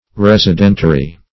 Residentiary \Res`i*den"tia*ry\, n.